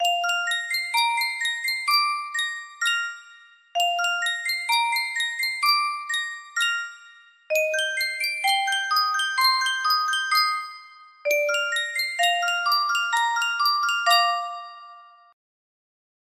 Yunsheng Music Box - Tchaikovsky 1812 Overture 4653 music box melody
Full range 60